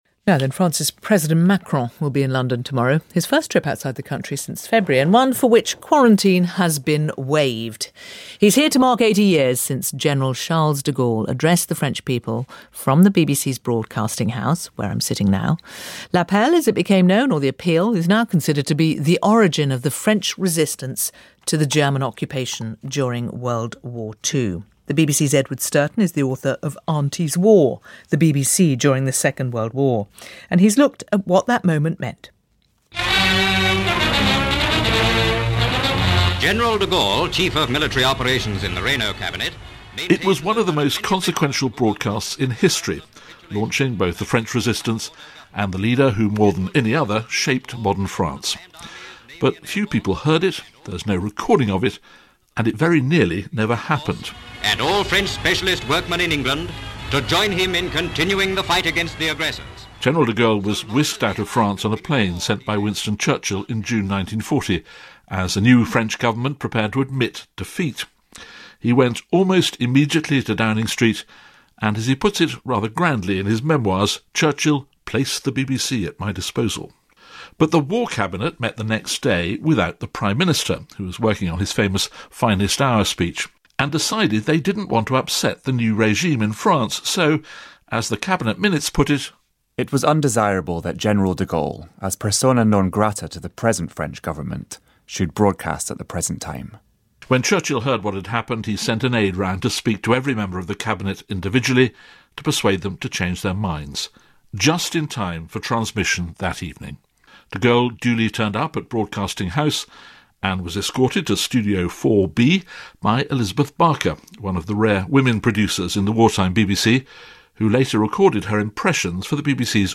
This report, on the 80th anniversary of that momentous broadcast was compiled by Edward Stourton for Radio 4's World at One.